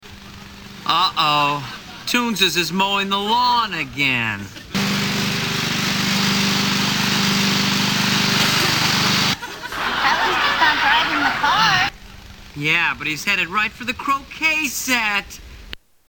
Lawn Mower
Category: Television   Right: Personal